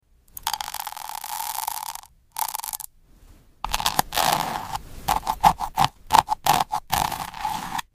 Golden Berry Toast Spread Asmr Sound Effects Free Download